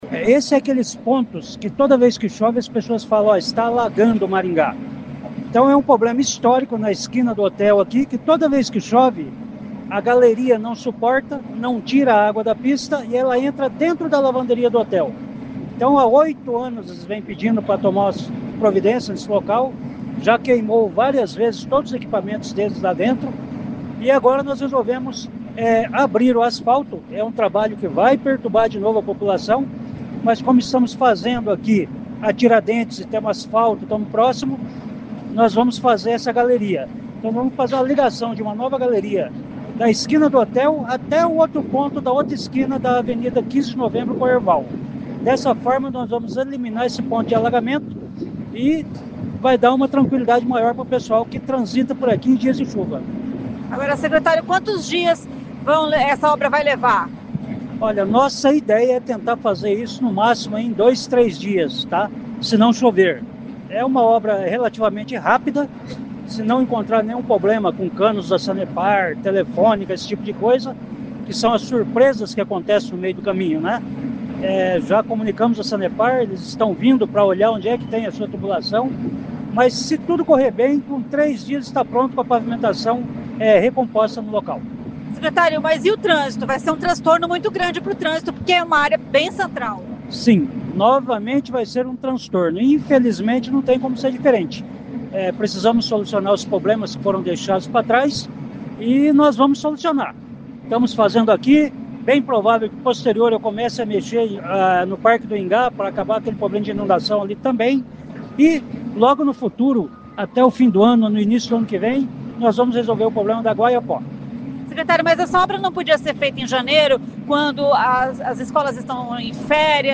O secretário de Infraestrutura, Vagner Mussio, explica a obra.